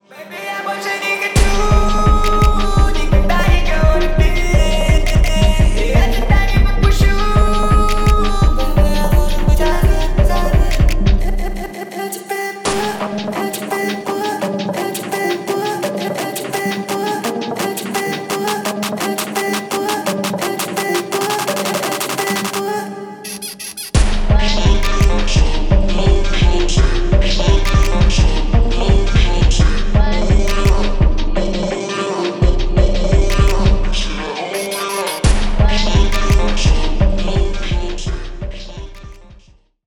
Поп Музыка
ритмичные